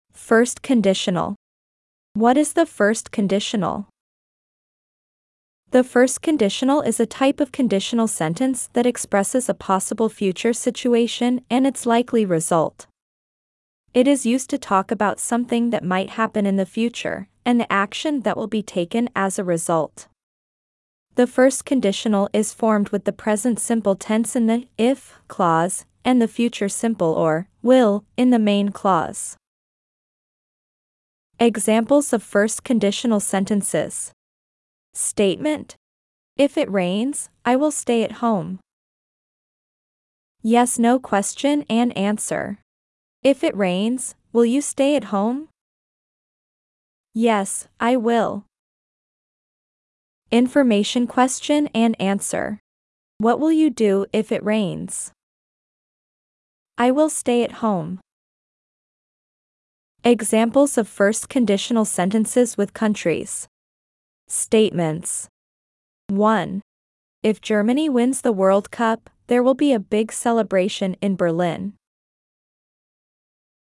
As you progress through the exercises and listen to the example statements and dialogs, you’ll not only strengthen your grasp on key English tenses and sentence structures but also enrich your vocabulary with the English names of countries from around the globe.